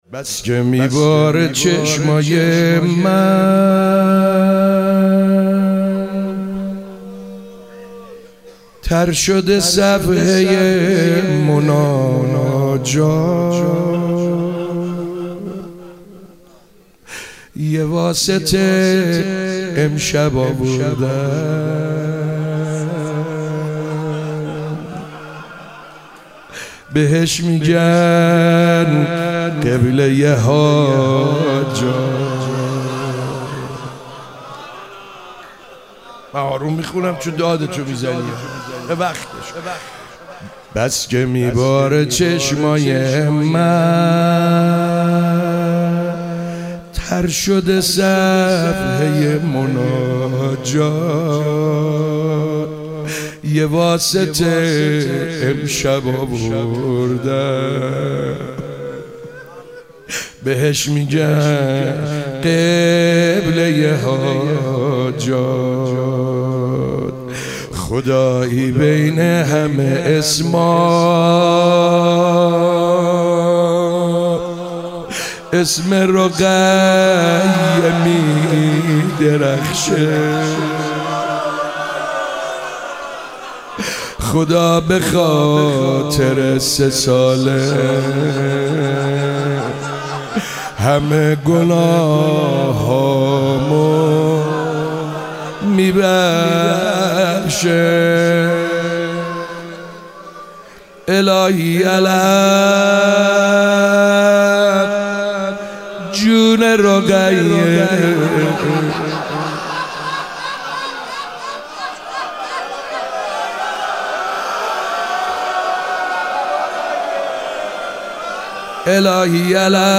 شب 3 ماه رمضان97- روضه